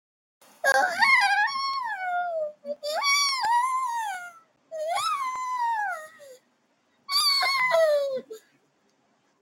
Animal Dying Sound Button - Free Download & Play
Animal Sounds Soundboard120 views